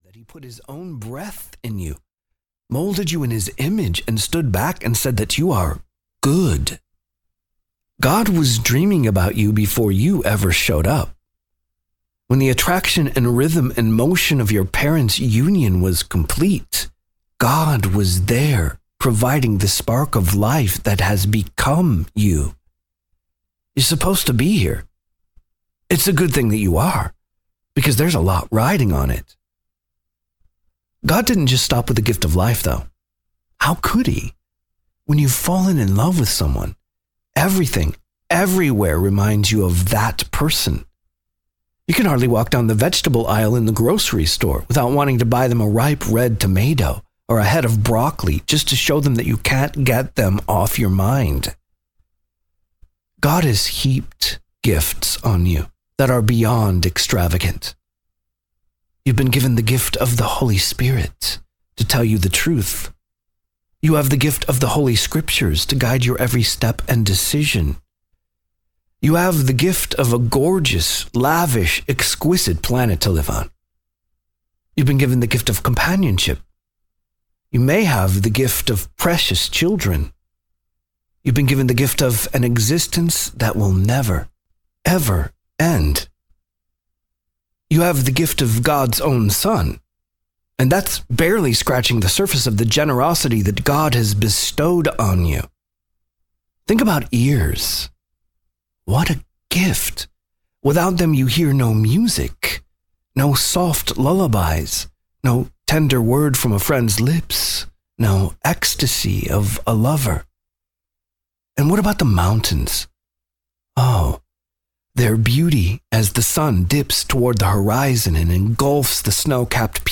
4.53 Hrs. – Unabridged